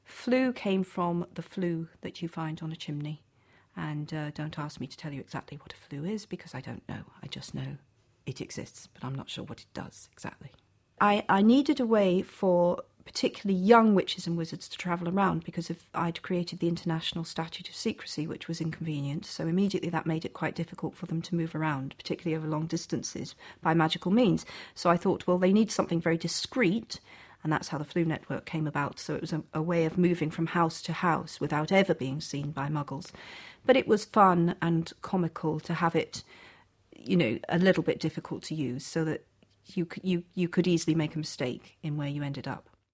ฟัง เจ.เค.โรว์ลิ่ง พูดคุยเกี่ยวกับแรงบันดาลใจสำหรับเครือข่ายฟลู